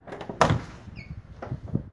真正的旧门SFX " 旧门打开
描述：一个逼真的，旧的开门声，例如RPG，FPS游戏。
声道立体声